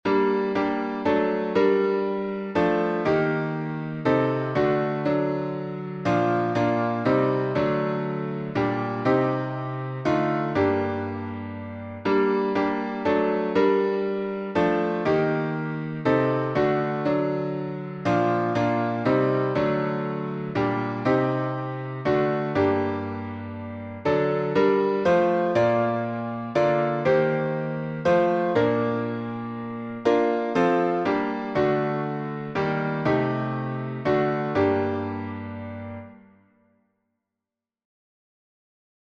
Catherine Winkworth (1827–1878), alt.Tune: NEUMARK by Georg Neumark (1621–1681)Key signature: B flat major (2 flats)Time signature: 3/4Meter: 9.8.9.8.8.8.Public Domain1.